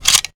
weapon_foley_drop_10.wav